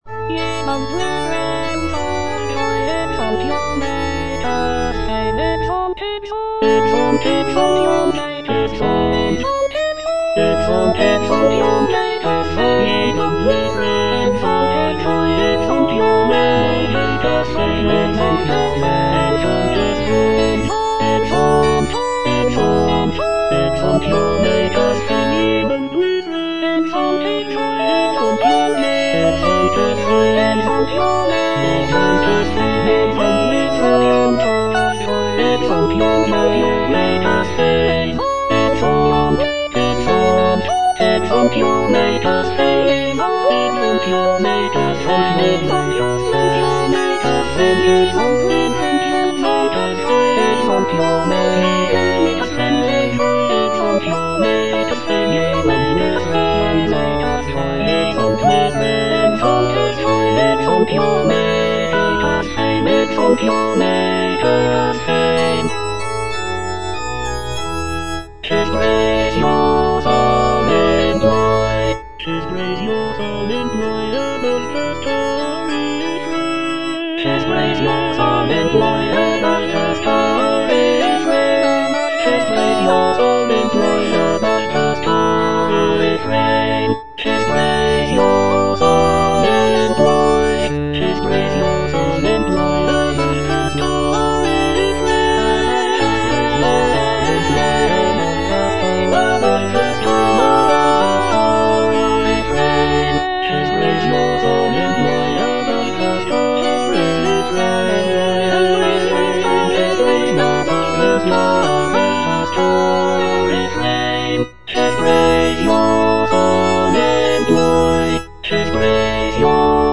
(A = 415 Hz)
(All voices)